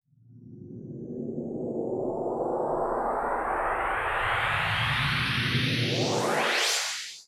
VTS1 Incast Kit Sound FX